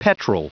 Prononciation du mot petrel en anglais (fichier audio)
Prononciation du mot : petrel